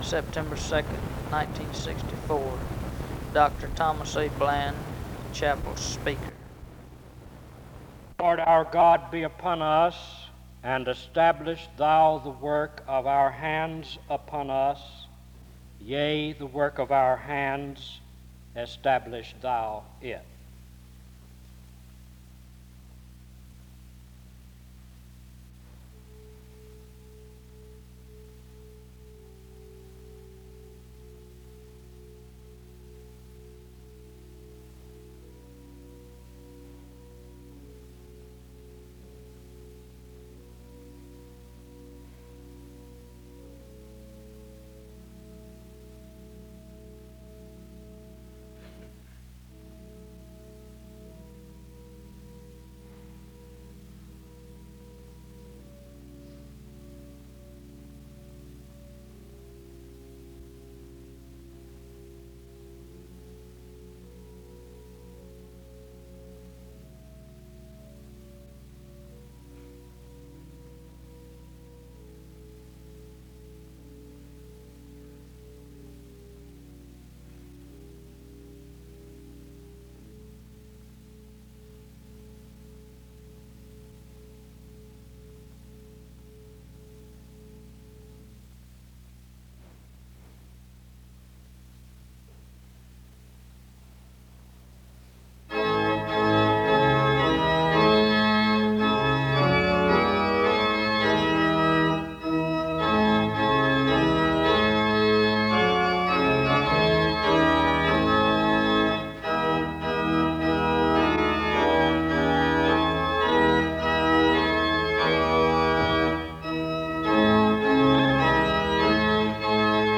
The service opens with a word of prayer and music from 0:00-4:34. There is a responsive reading from 4:58-6:28.
Another prayer takes place from 6:34-7:51. Music plays from 8:00-10:45.
SEBTS Chapel and Special Event Recordings SEBTS Chapel and Special Event Recordings